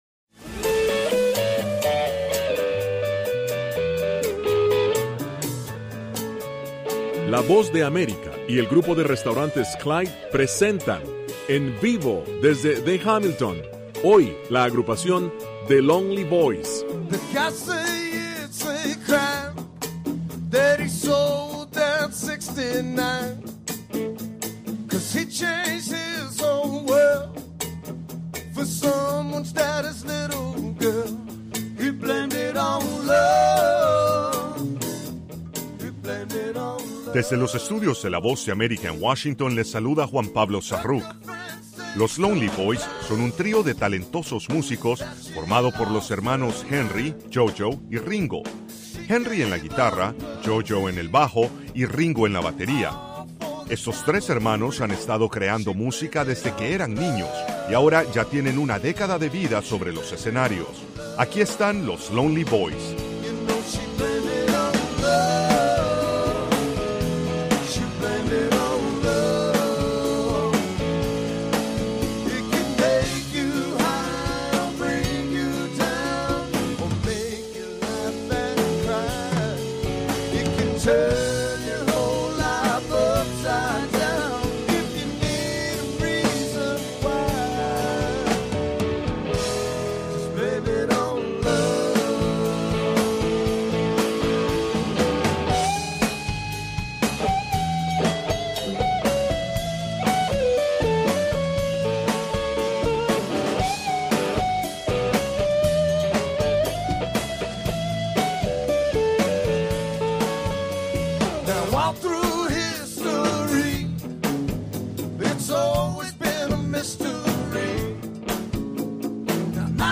Concierto
[Duración 30 minutos con cortes para comerciales].